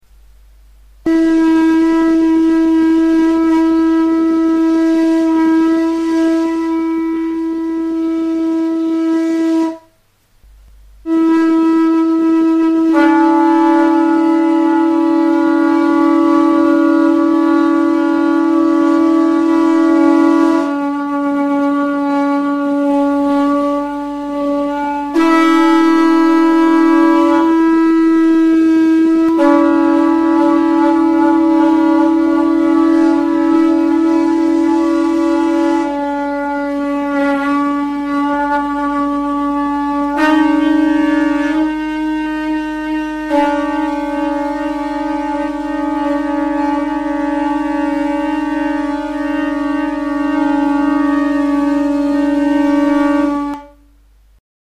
ITSAS KURKUILUA; CARACOLA | Soinuenea Herri Musikaren Txokoa
Aerophones -> Lip vibration (trumpet) -> Natural (with / without holes)
Punta zulaturik tronpeta gisako ahokadura duen itsas kurkuilua da.